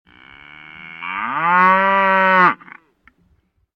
MOOOOO.mp3